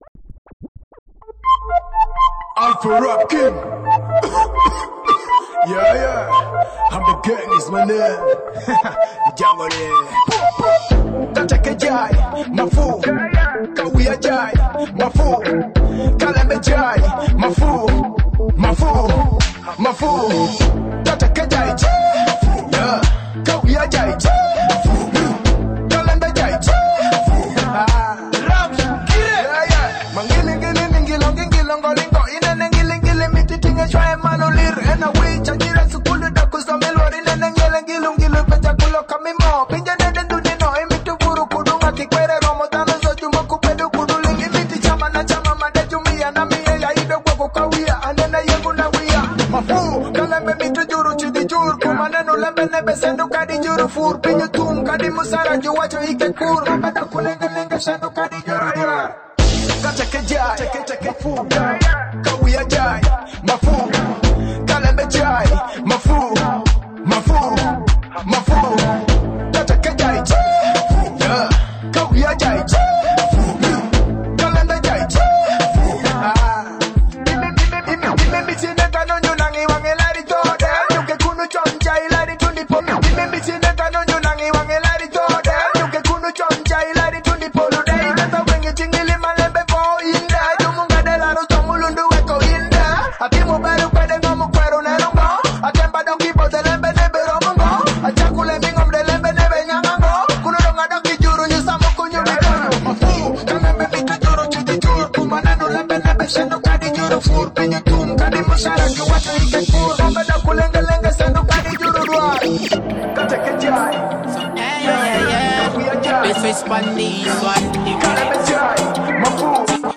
Latest Alur Music